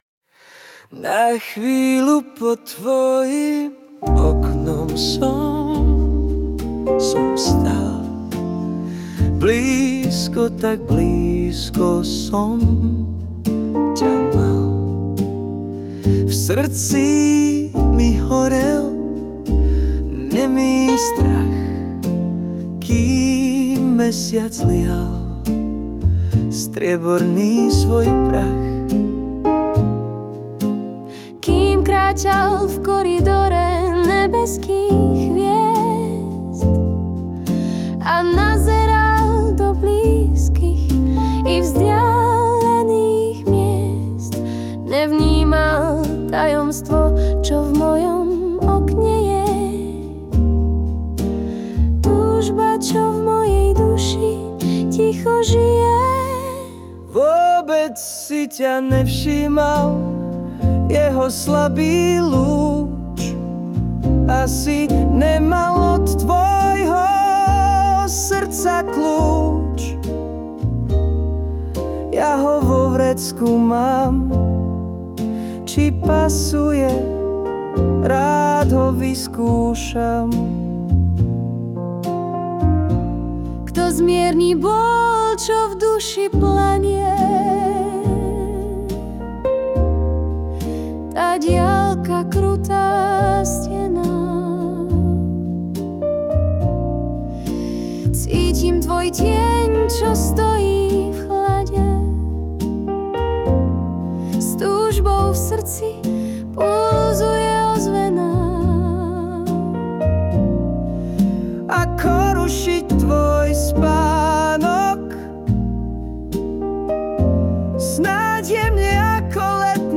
HUdba a spev AI